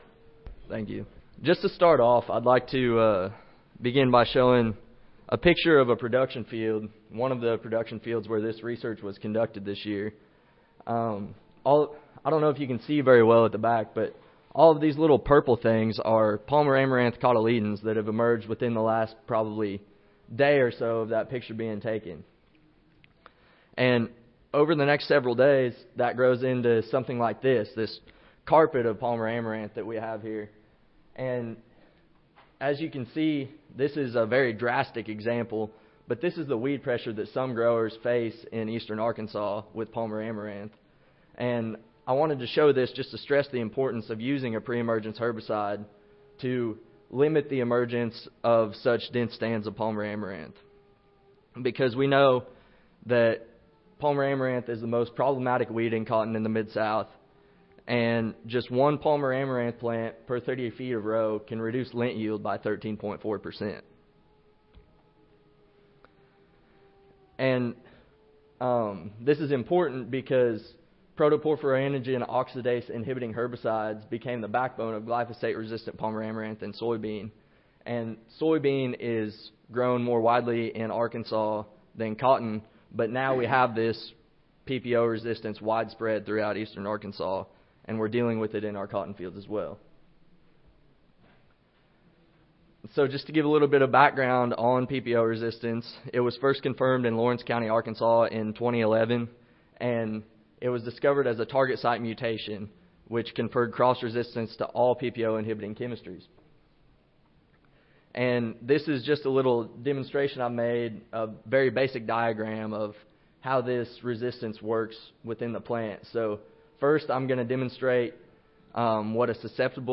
Recorded Presentation Various preemergence herbicides which are already labeled for use in cotton were evaluated for the control of two populations of PPO-resistant Palmer amaranth.